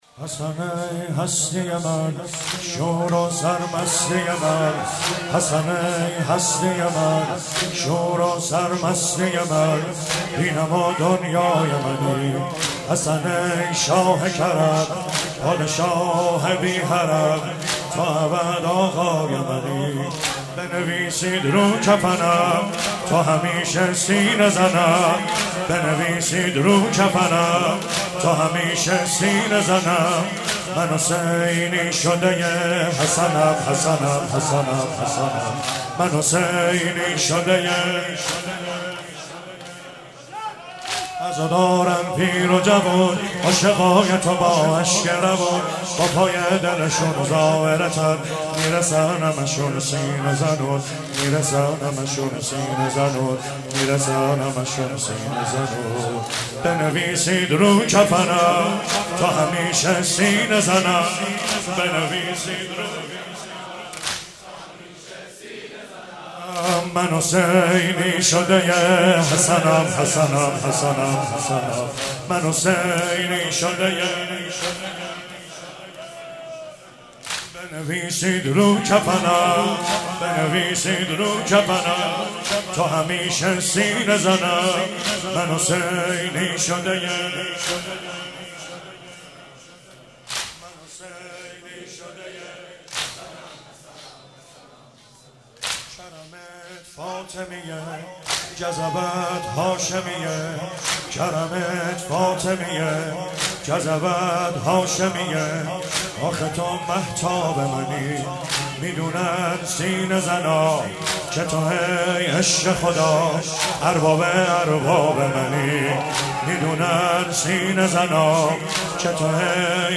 25 صفر97 - شب سوم - واحد - حسن ای هستی من